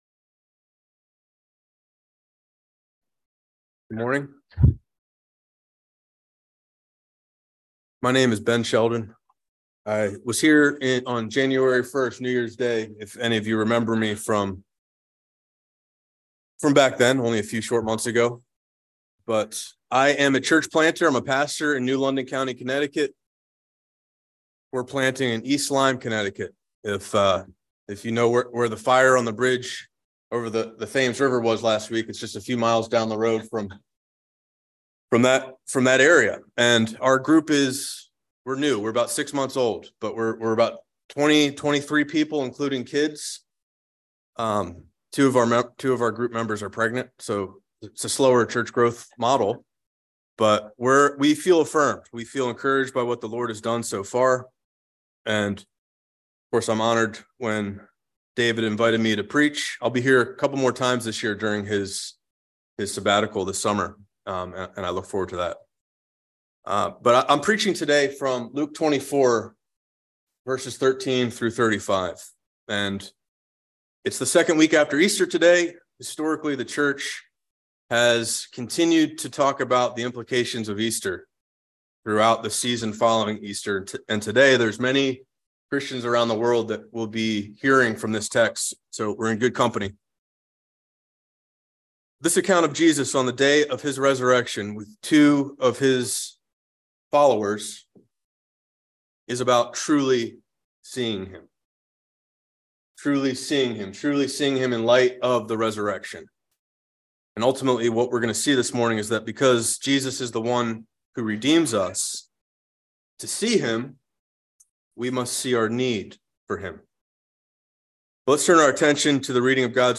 by Trinity Presbyterian Church | Apr 24, 2023 | Sermon